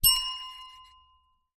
Hotel Service Desk Bell Sound Button: Unblocked Meme Soundboard